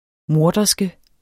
Udtale [ ˈmoɐ̯dʌsgə ]